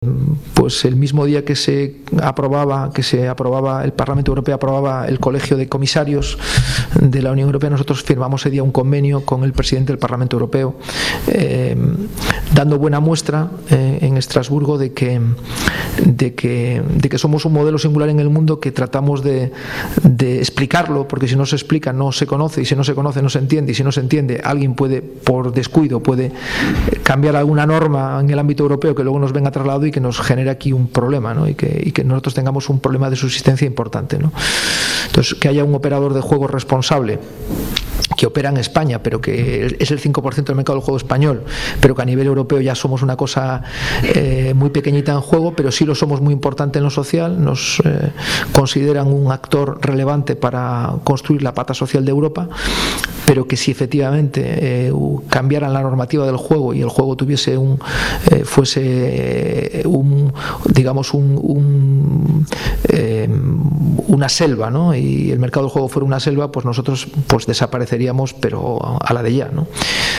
Ante una audiencia que llenó a rebosar el Salón Sardinero del Hotel Real de Santander